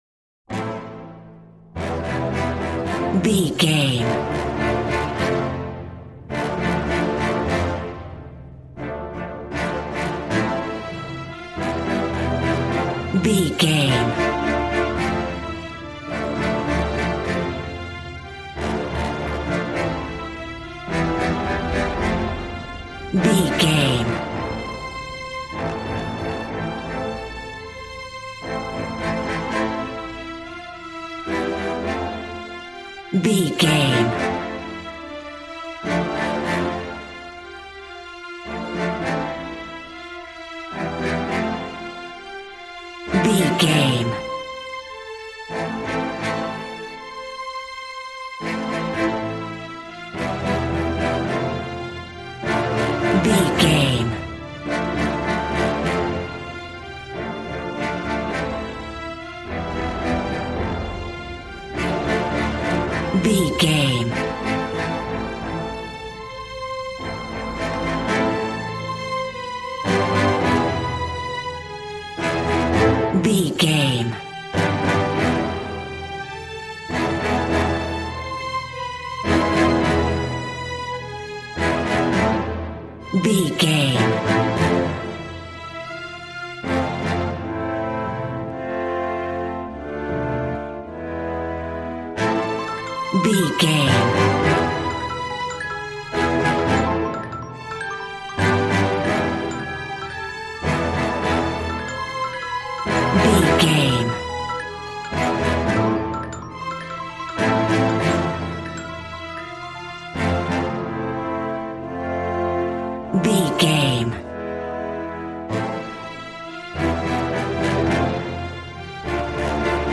Aeolian/Minor
regal
cello
double bass